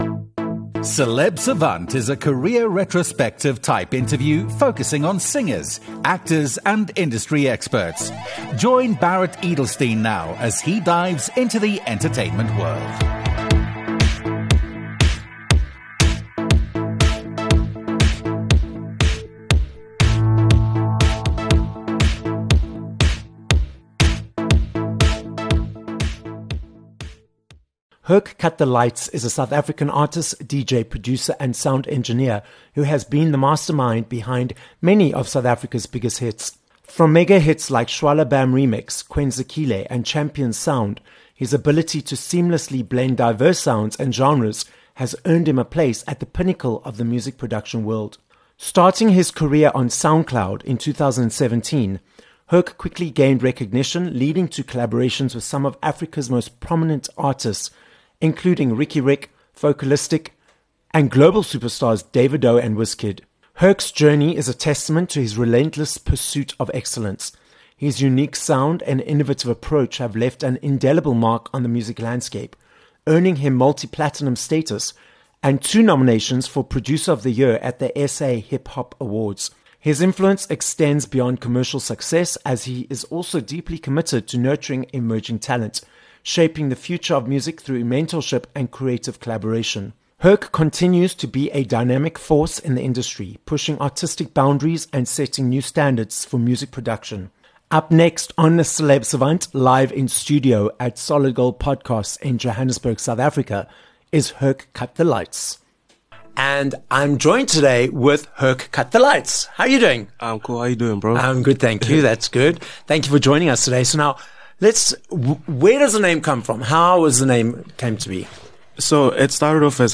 This episode was recorded live in studio at Solid Gold Podcasts, Johannesburg, South Africa.